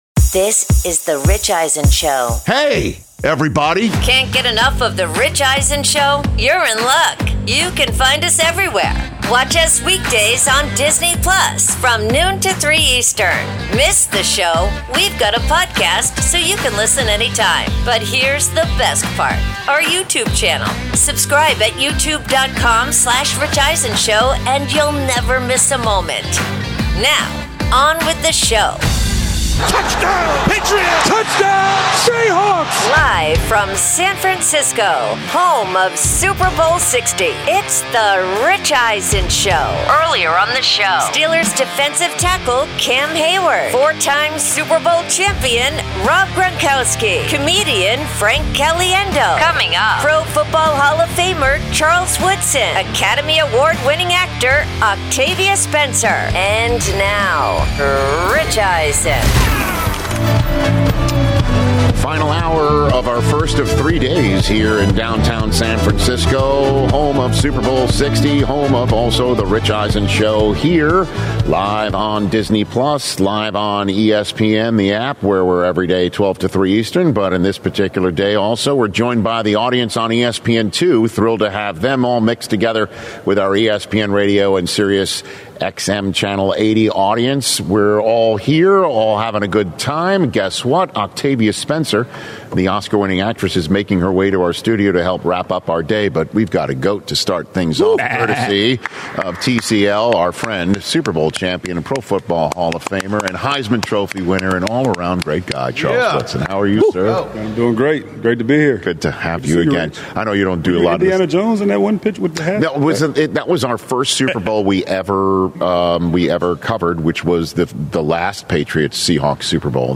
Hour 3: Live from Super Bowl LX with Charles Woodson, Octavia Spencer & O’Shea Jackson Jr.